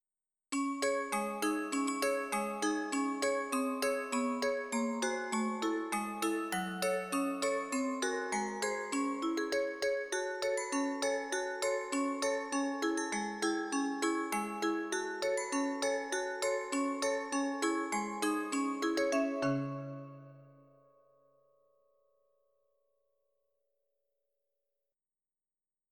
folk song America>